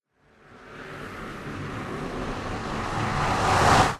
MinecraftConsoles / Minecraft.Client / Windows64Media / Sound / Minecraft / portal / trigger.ogg
trigger.ogg